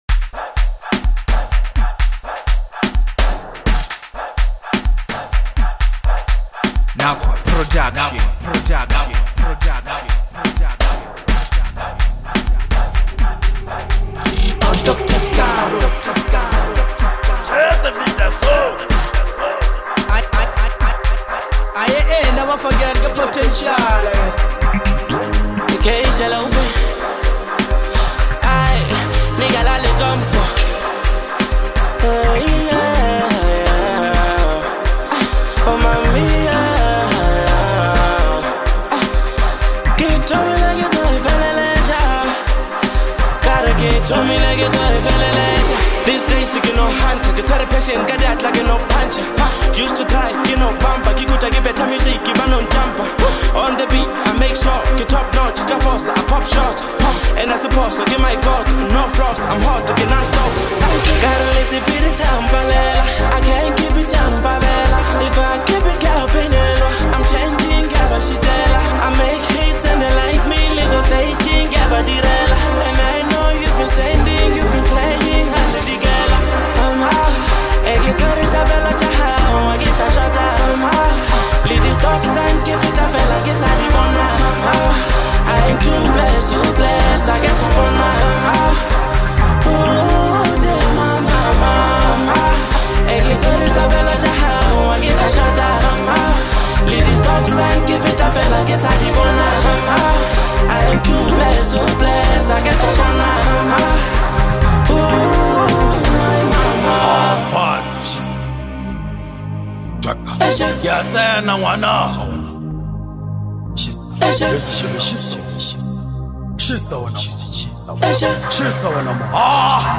aggressive sound and catchy hooks
fiery,street smart verses